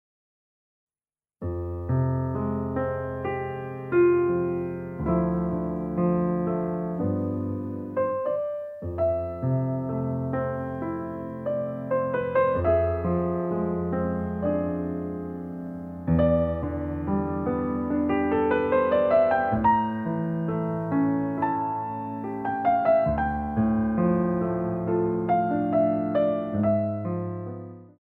Compositions for Ballet Class
Exercice préparatoire